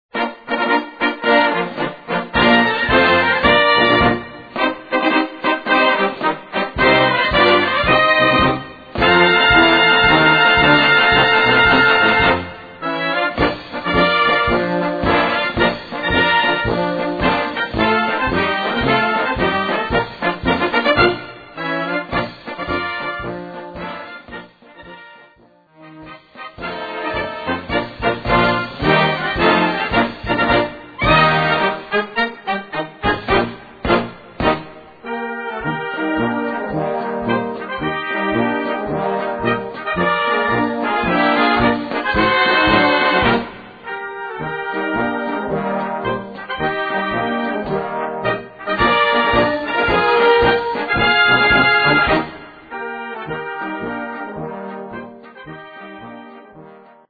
Kategorie Blasorchester/HaFaBra
Unterkategorie Strassenmarsch
Besetzung Ha (Blasorchester)